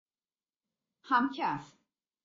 جلوه های صوتی
دانلود صدای همکف آسانسور از ساعد نیوز با لینک مستقیم و کیفیت بالا